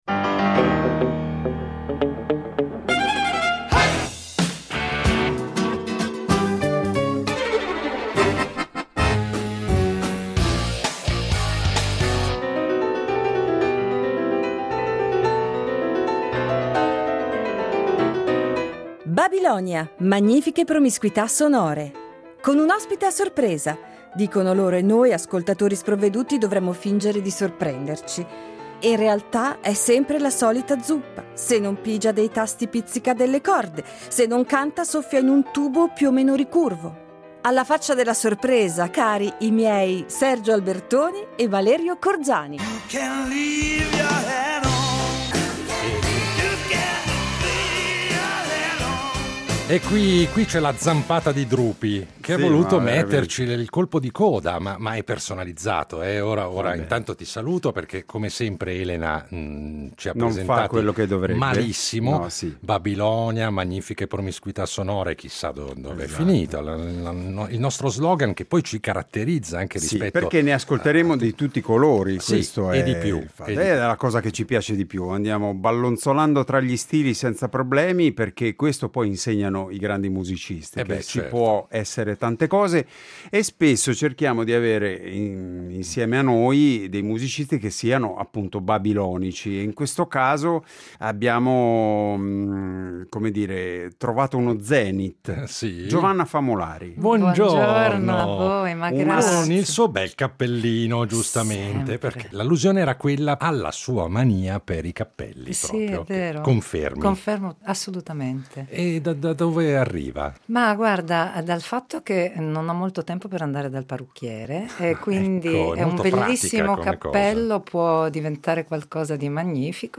La nostra graditissima ospite di oggi è un’artista triestina decisamente babilonica